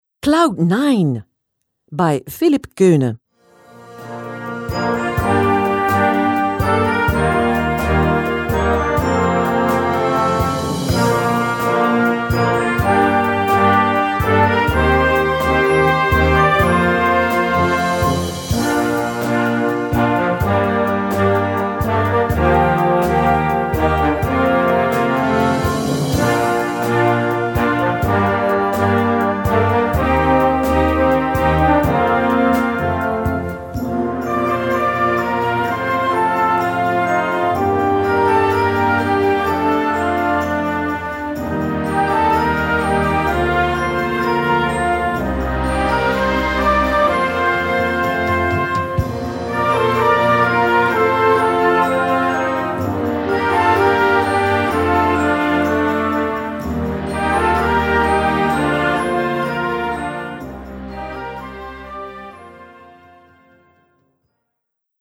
Besetzung: Blasorchester
dynamische, muntere Komposition